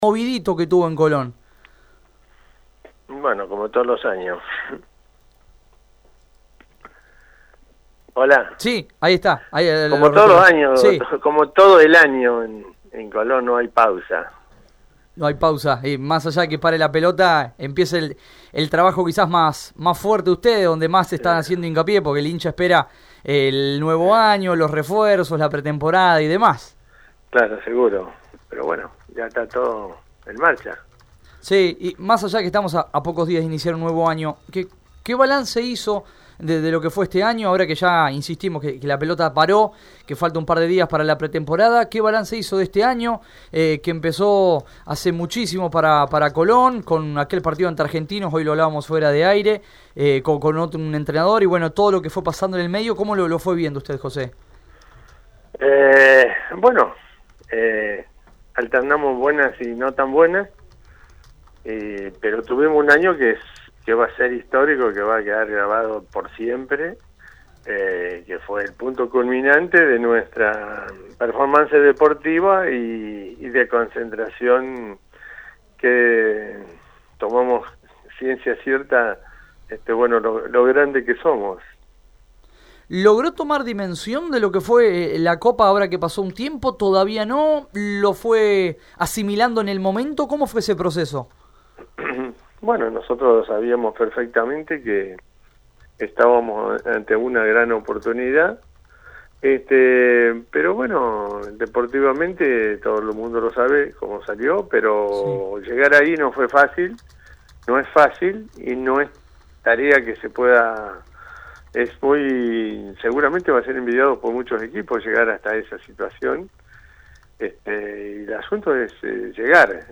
En comunicación con Radio EME Deportivo